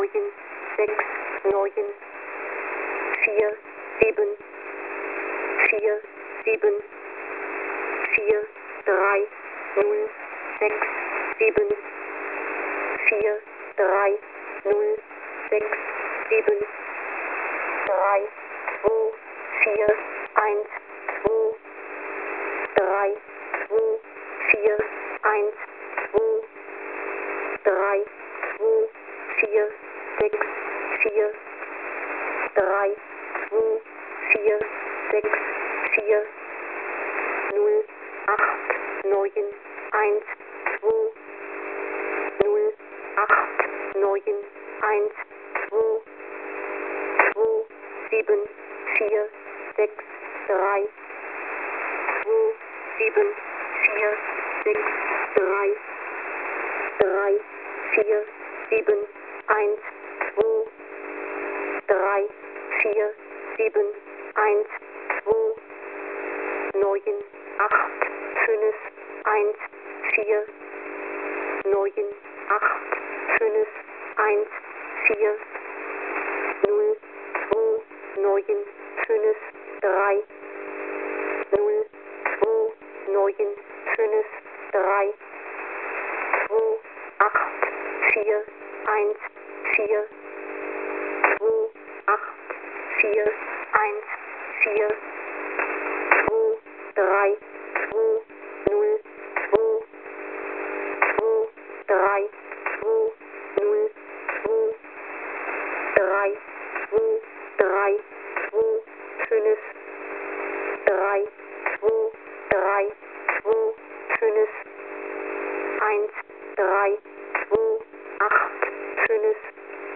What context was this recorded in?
Mode: USB + Carrier websdr_recording_start_2019-03-29T19_33_29Z_5442.0kHz.wav